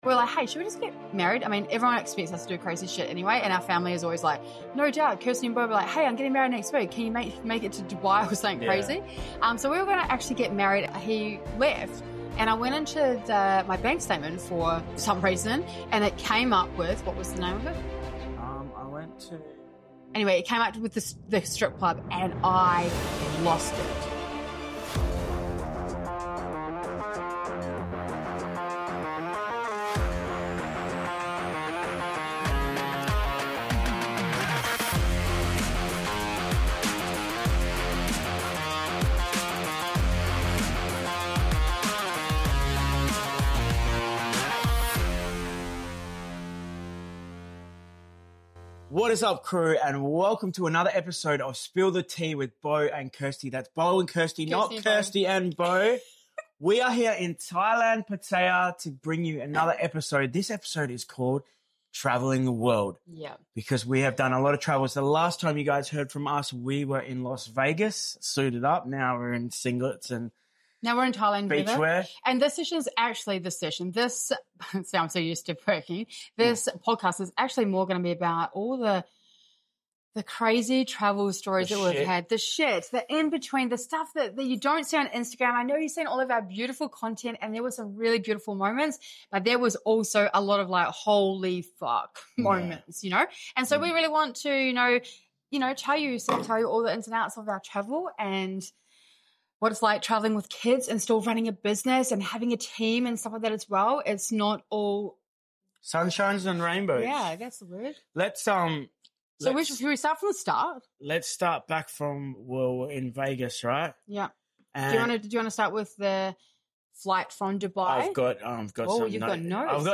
Okay, crew, it’s time to spill the tea on what really happens when you’re traveling the world with kids, running a business, and trying to stay sane. We’re coming to you straight from the beaches of Pattaya, Thailand—way more chill than the last time you heard from us in Vegas.